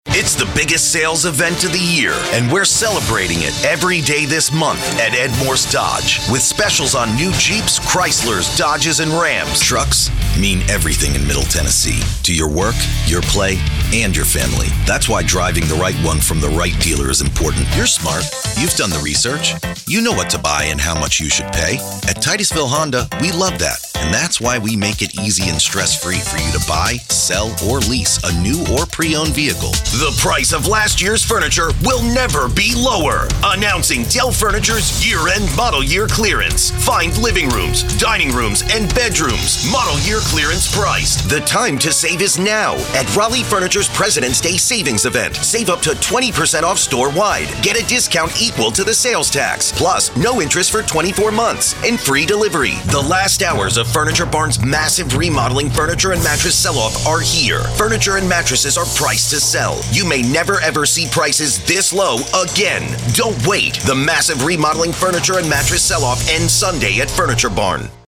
A friendly male voice with the ability to provide a strong retail and commercial voice for sales and promotion, trailers, video game and animated characters, e-books and e-learning, and so much more...
Middle Aged
Full soundproof home studio